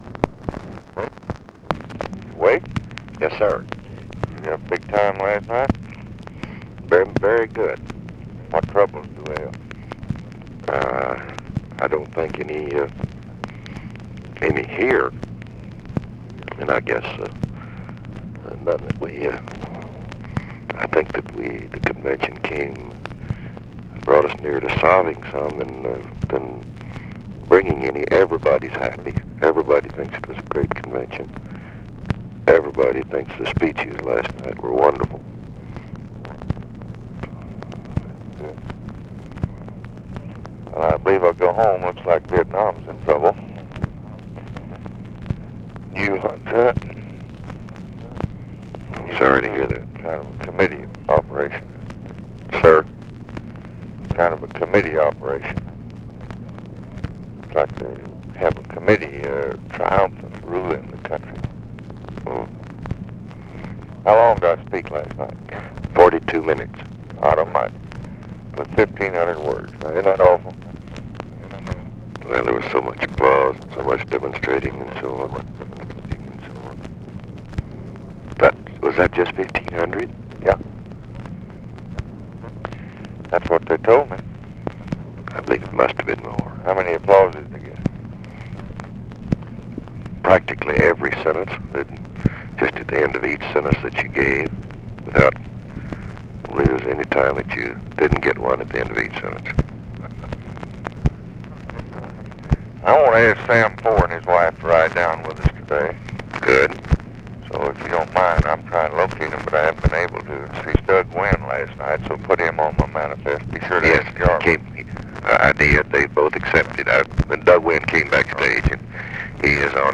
Conversation with WALTER JENKINS, August 28, 1964
Secret White House Tapes